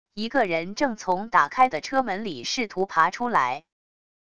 一个人正从打开的车门里试图爬出来wav音频